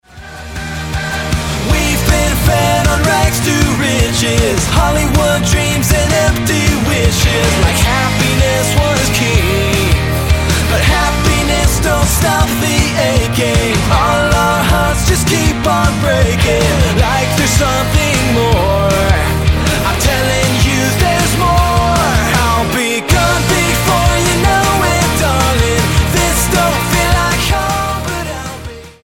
power trio
Style: Rock